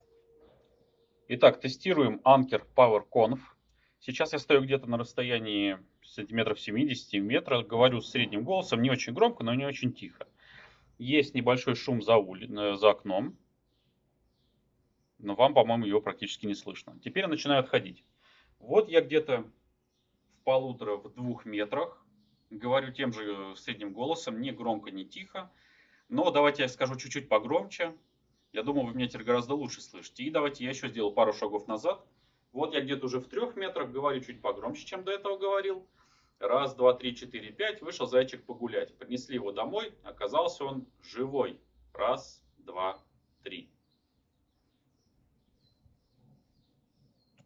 Их цифровых технологий производитель отмечает использование специального алгоритма DSP, который выравнивает громкость голоса, подавляет эхо в реальном времени до 70 дБ и снижает уровень шума на 20 дБ.
Собеседник на качество и громкость не жаловался, при условии, что расстояние до спикерфона составляет 1-1,5 метра и вы разговариваете с обычной громкостью (такой же, с какой вы спокойно разговариваете по телефону).
Внешний шум при этом действительно очень неплохо гасится.